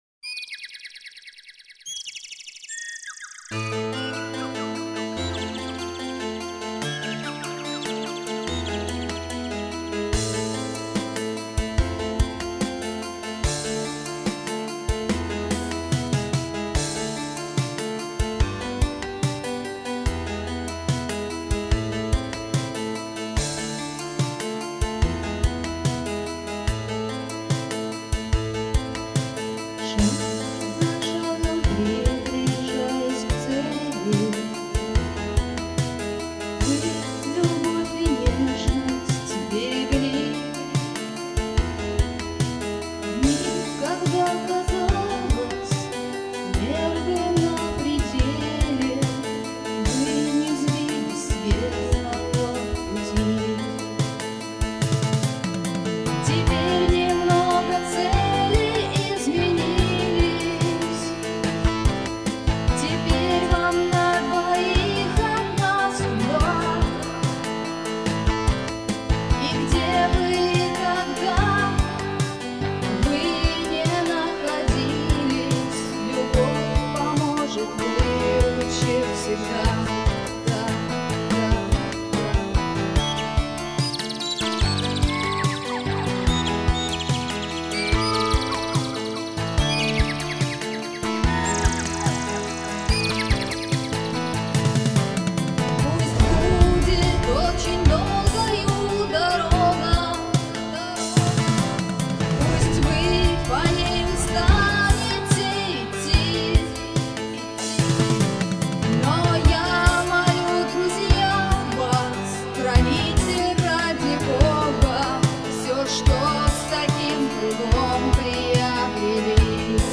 Эта песня была написана ко дню свадьбы друзей.